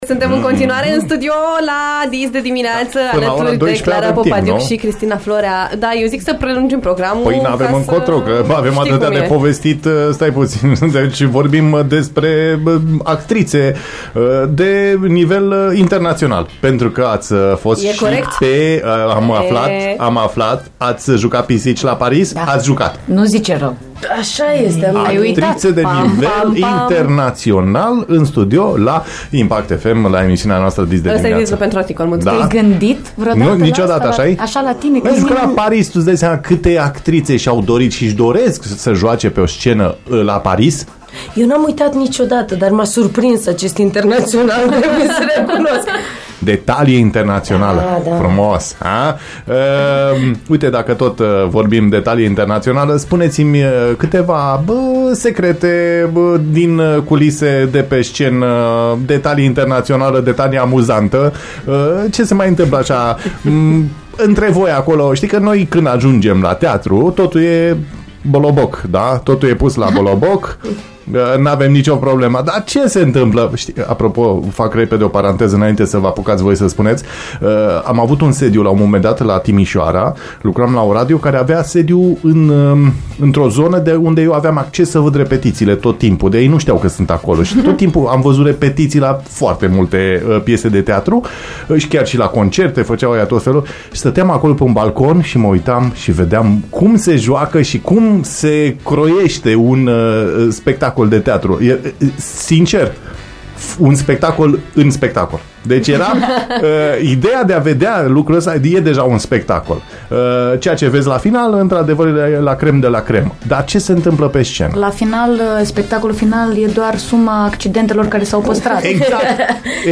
Le-am luat prin surprindere cu titulatura „actrițe de talie internațională”, dar și ele ne-au surprins cu talentul pe care îl au la microfon, ca oameni de radio.